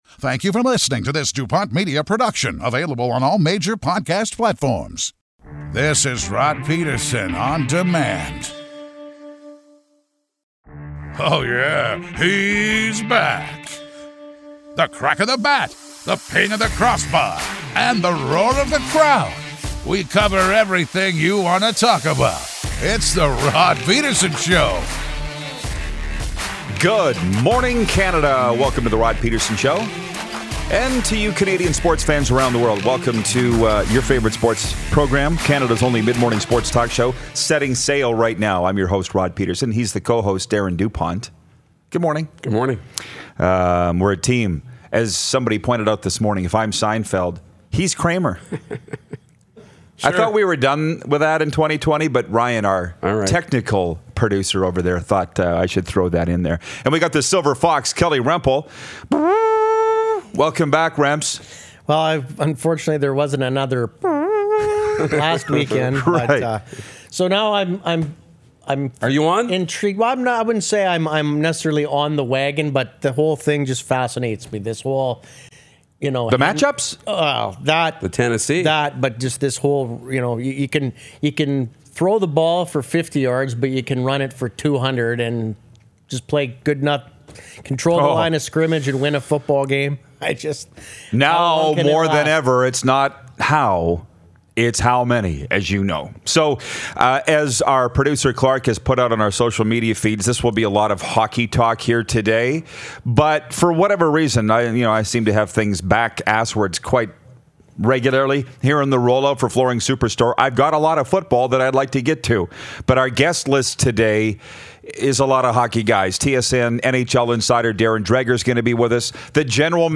TSN Hockey Insider Darren Dreger gives us a call!